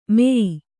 ♪ meyi